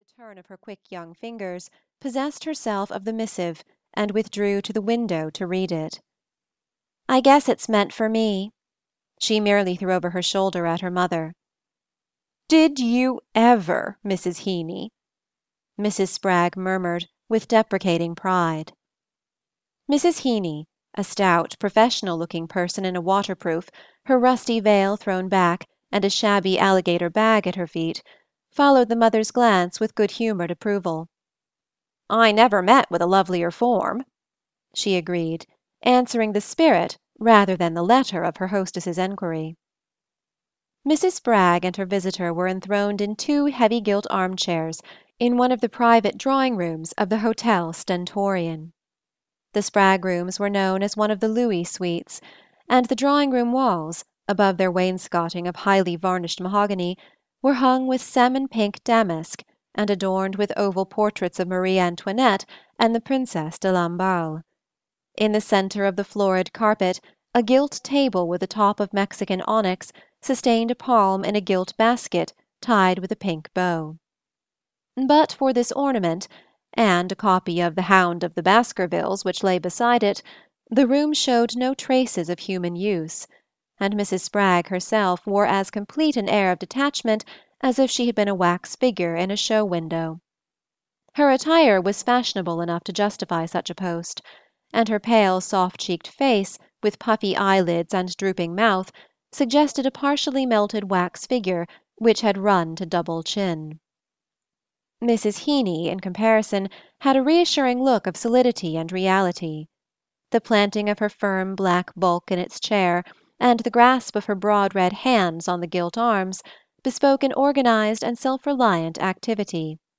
The Custom of the Country (EN) audiokniha
Ukázka z knihy